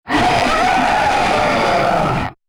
taunt1.wav